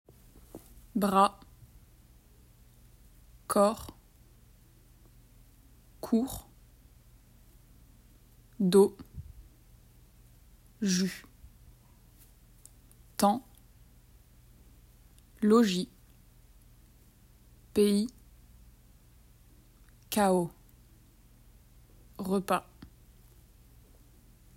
Listen and repeat these common words in which the final -s is silent.